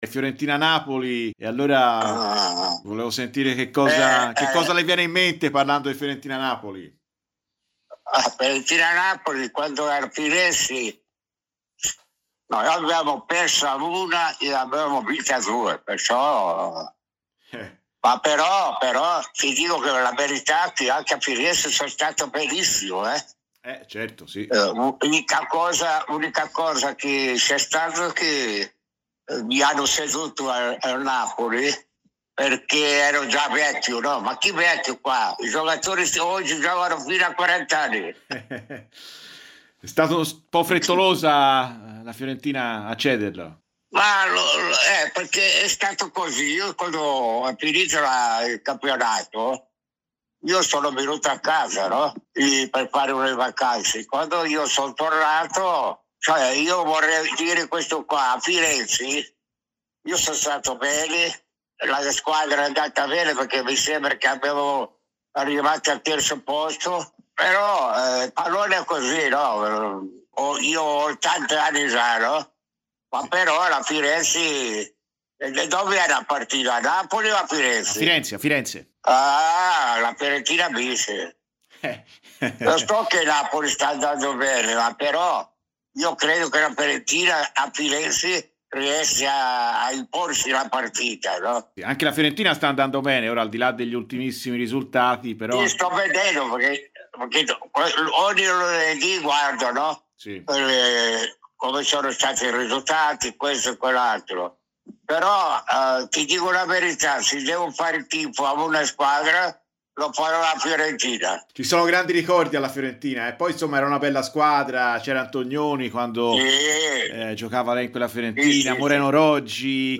Sergio Clerici, ex calciatore brasiliano passato in Italia anche dalla Fiorentina, ha parlato oggi a Radio Firenzeviola durante 'Viola amore mio'.